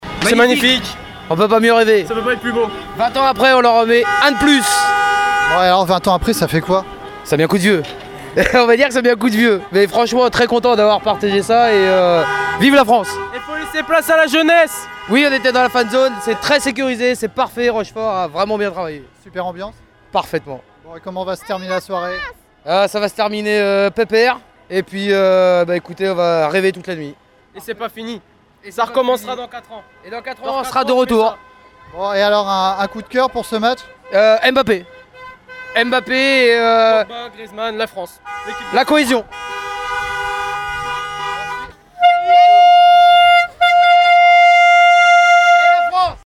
Dans les rues du centre-ville de Rochefort, partout la même ambiance. Concerts de klaxons et cris de joie :